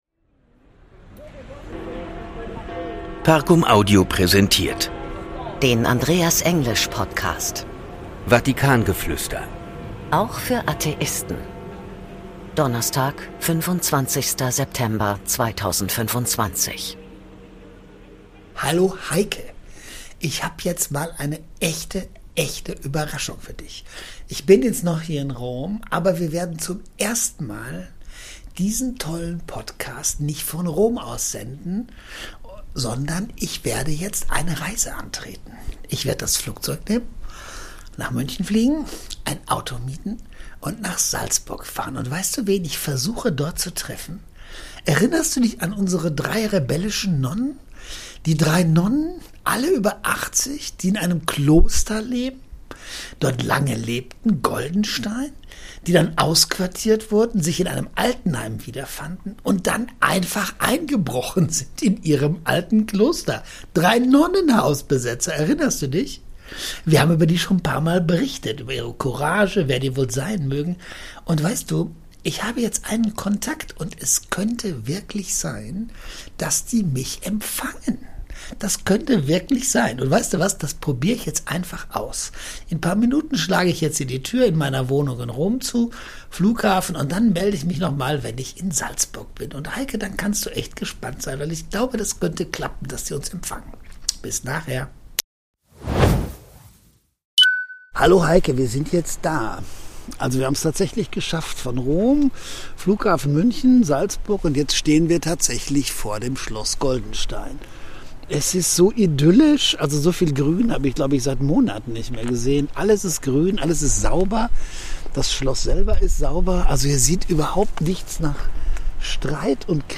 In dieser Folge ist Andreas zu Besuch im Kloster Goldenstein. Die drei Ordensschwestern öffnen ihm ihr Herz und blicken zurück auf ein bewegtes Leben zwischen Glauben, Gemeinschaft und persönlicher Herausforderung.